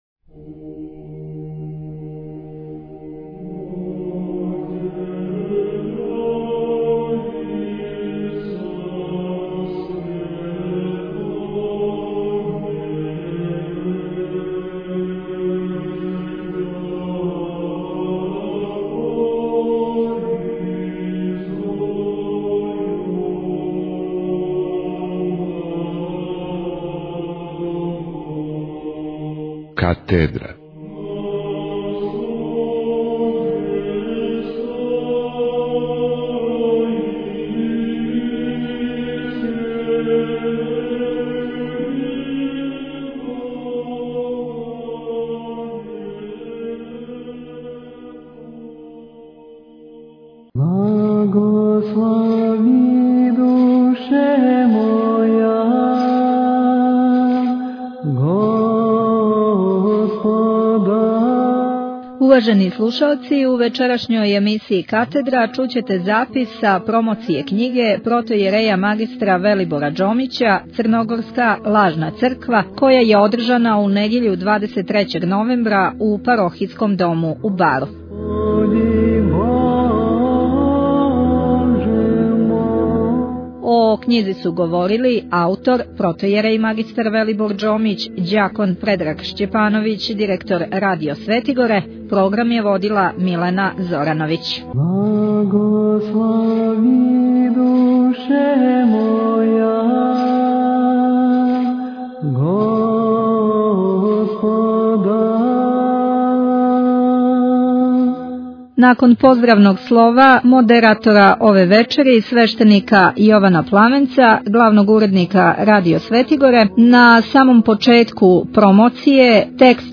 Формат: MP3 Mono